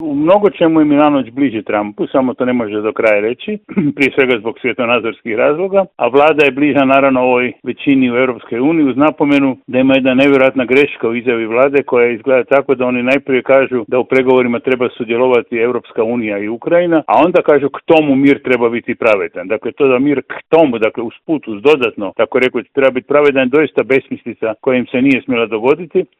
Razgovor je za Media servis komentirao politički analitičar Žarko Puhovski: